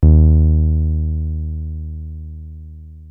303 D#2 6.wav